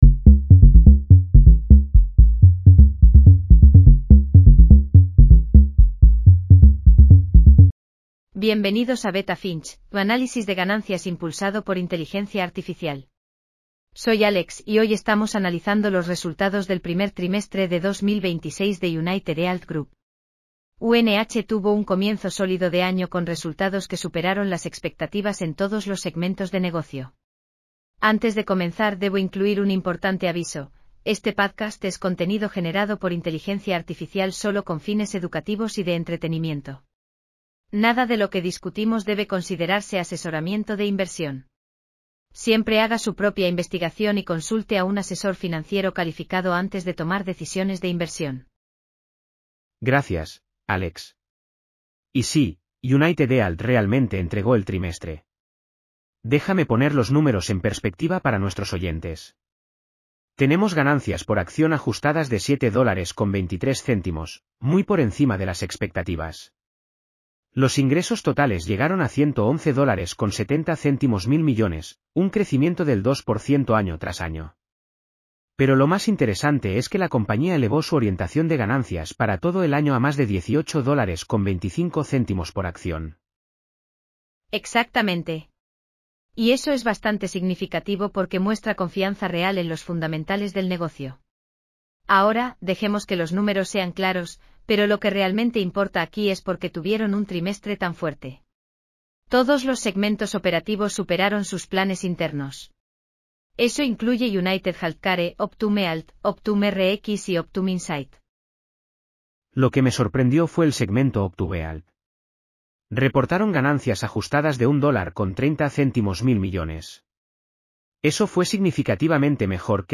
UnitedHealth Q1 2026 earnings call breakdown.